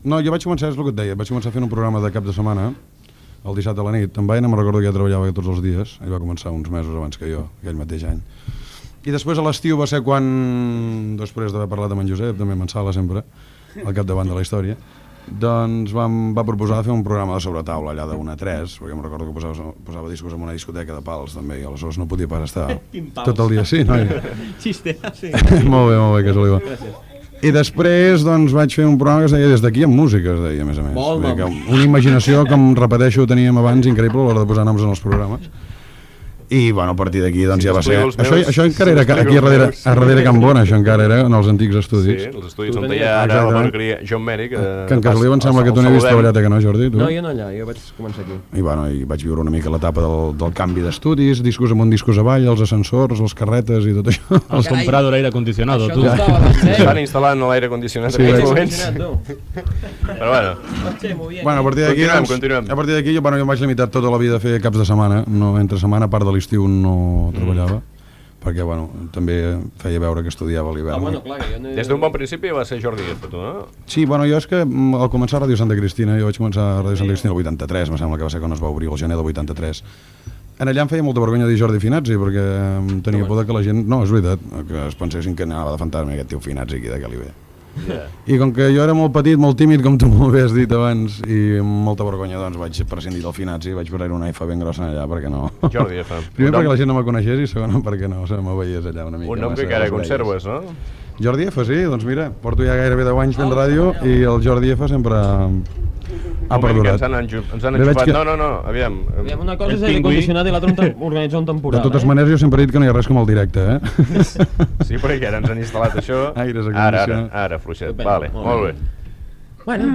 Entreteniment
FM
Fragment extret de l'arxiu sonor de Ràdio Platja d'Aro